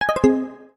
success.wav